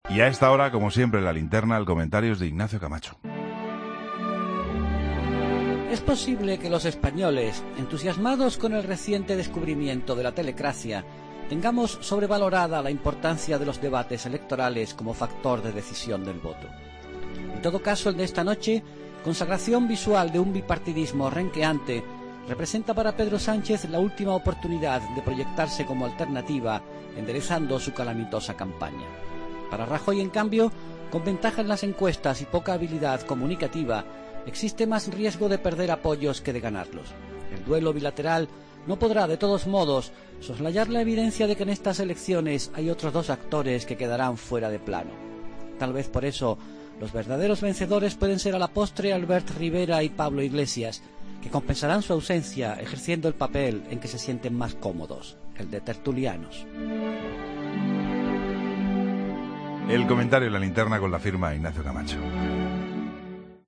Comentario de Ignacio Camacho en La Linterna